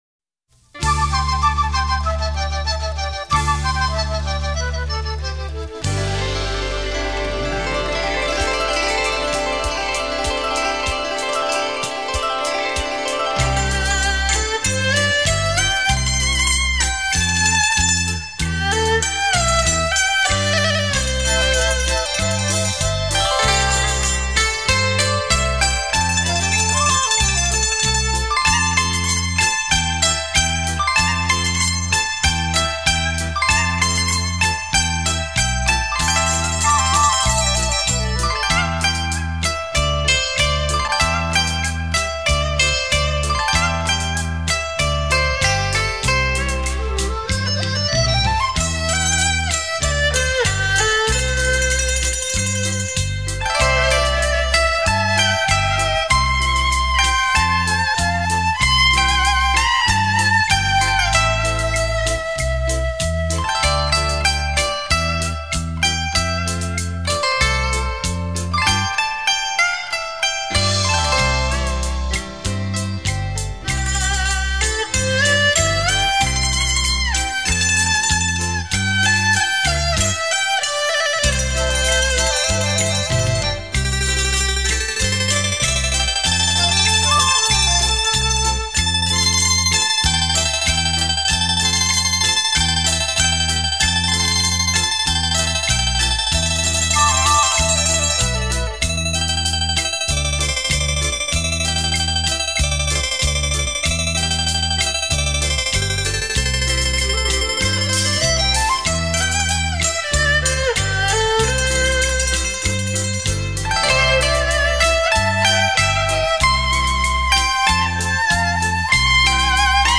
古筝、横笛、扬琴、琵琶、胡琴等联合主奏